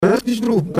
という不機嫌そうなリバース・スピーチが現れていたのである。